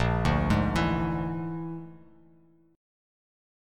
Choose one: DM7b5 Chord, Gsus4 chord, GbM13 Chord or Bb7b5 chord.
Bb7b5 chord